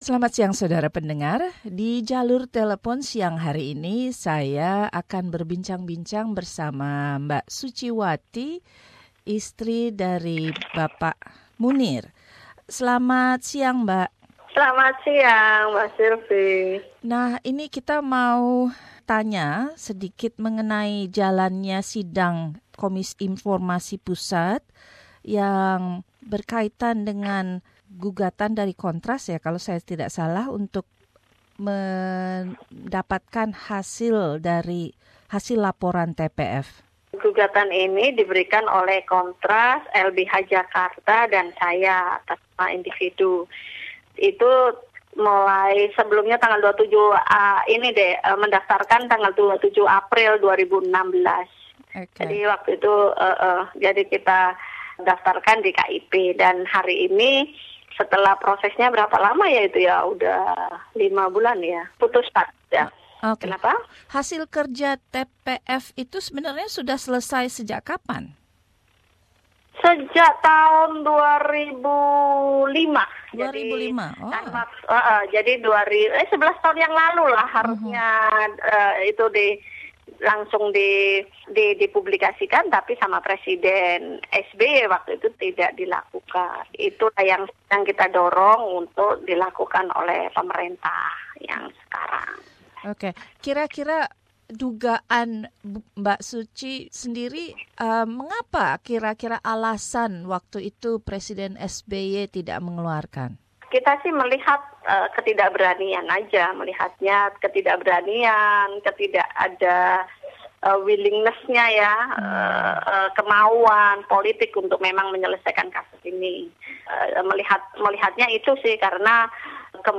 Wawancara dengan Ibu Suciwati Munir mengenai usahanya bersama KONTRAS (Komisi Untuk Orang Hilang dan Korban Tindak Kekerasan) dan LBH Jakarta menuntut pemerintah Indonesia untuk membuka laporan dari Tim Pencari Fakta kasus Munir.